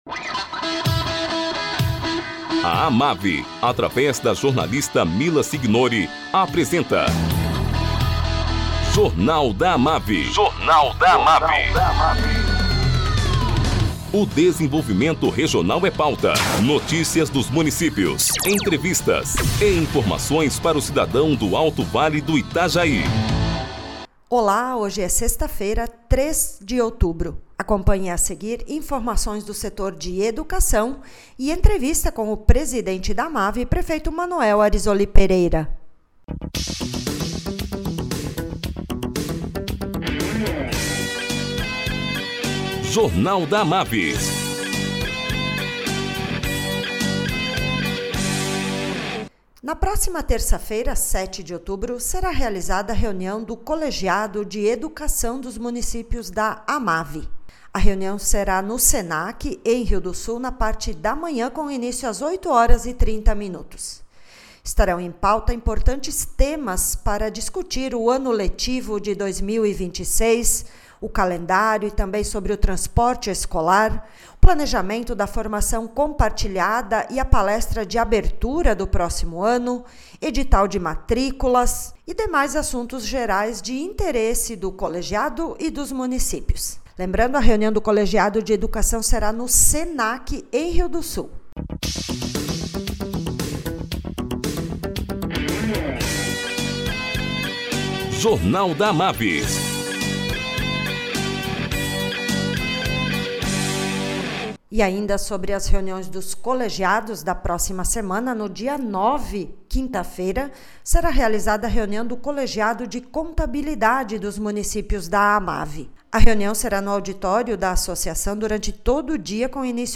Presidente da AMAVI, prefeito Manoel Arisoli Pereira, fala sobre a licitação compartilhada para contratação de empresa especializada na elaboração de projetos de limpeza de rios, lançada pelo CISAMAVI.